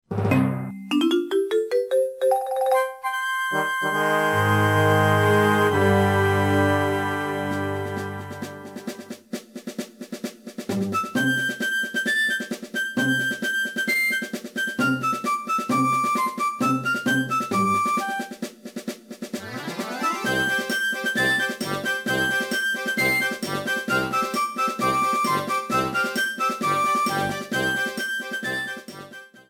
Ripped from the remake's files